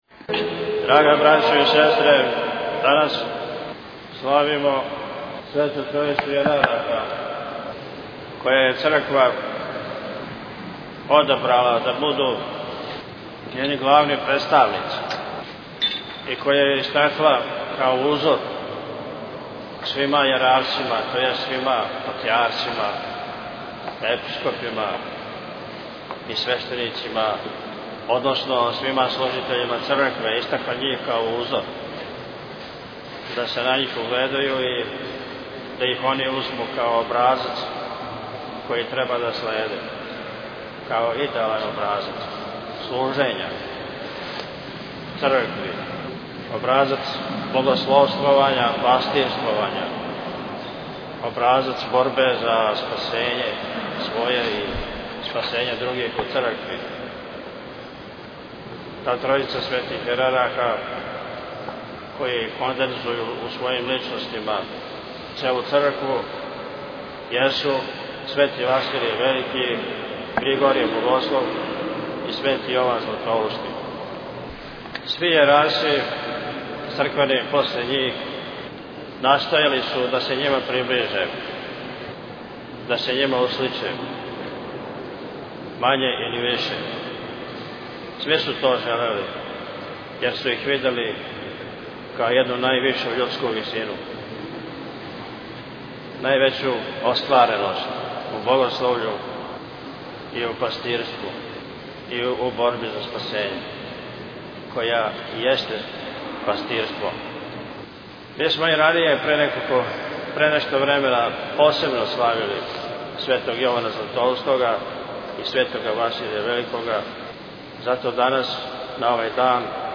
Епископ Атанасије (Ракита) на Света Три Јерарха служио у београдском Храму Светога Марка | Радио Светигора
Tagged: Бесједе Наслов: Episkop Atanasije (Rakita) Албум: Besjede Година: 2011 Величина: 12:08 минута (1.74 МБ) Формат: MP3 Mono 11kHz 20Kbps (VBR) Његово Преосвештенство викарни Епископ Хвостански Атанасије (Ракита) служио је 12. фебруара на Света Три Јерарха Свету Архијерејску Литургију у београдском Храму Светога Марка.